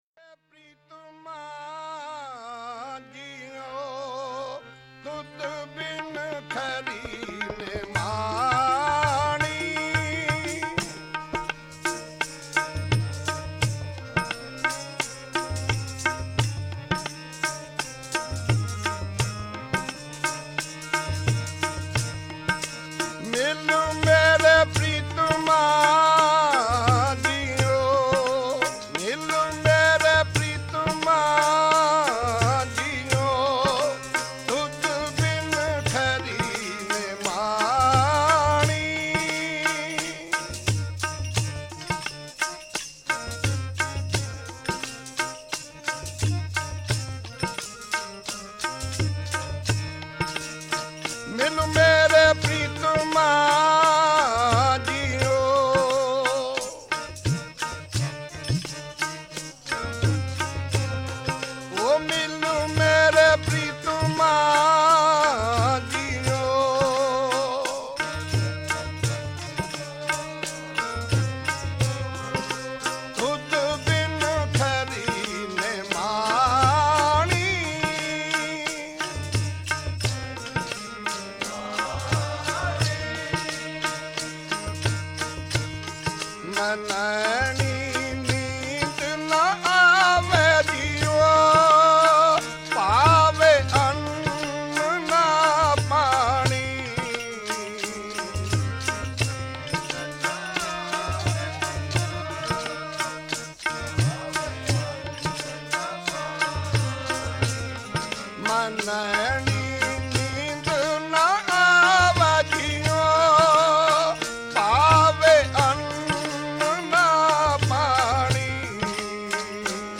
I aint able to access the link...but the Shabad seems highly enticing (I LOVE the Bairagi genre of Kirtan...if i can call it so)...so help plz!
This shabad is beautiful and the tabla playing is amazing!
He did so much to promote tabla in the UK, teaching hundreds of students and can justifiably be regarded as one of the leading Sikh tabla players in recent decades.